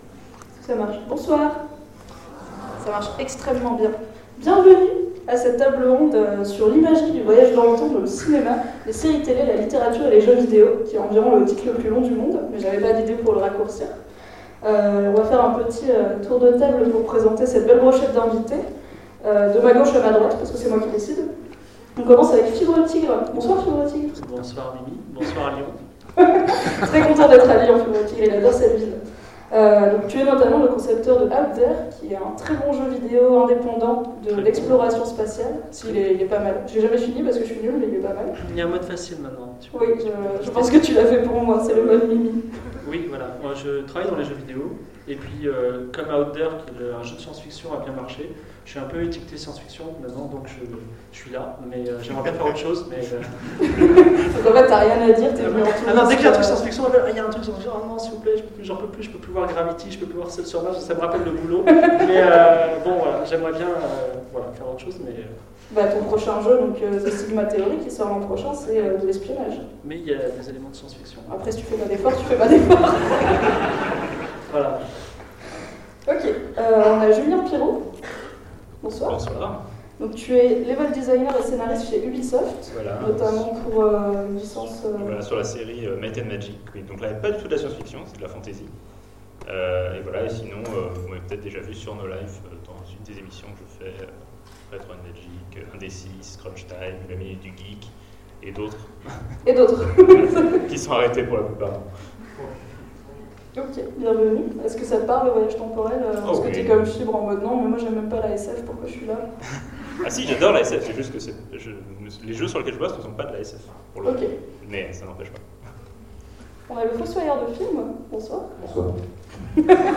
Intergalactiques 2015 : Conférence Imagerie du voyage dans le temps
Attention, il manque les quelques minutes de fin.